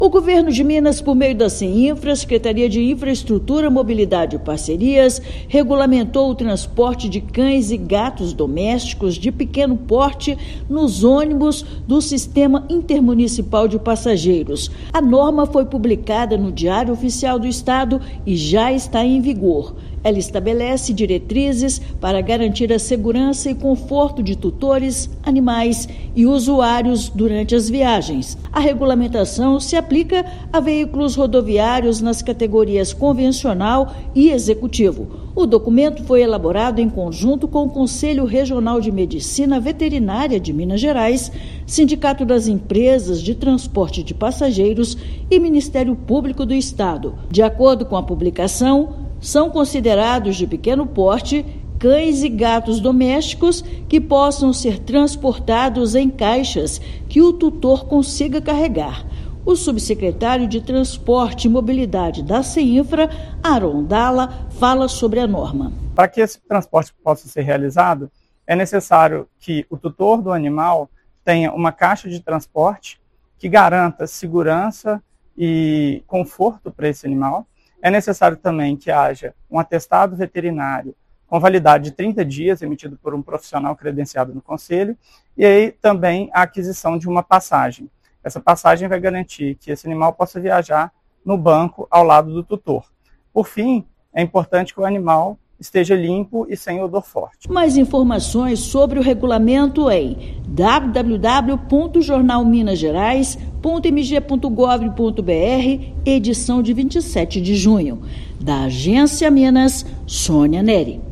Medida exige caixa adequada, atestado veterinário e estabelece limite de dois animais por viagem. Ouça matéria de rádio.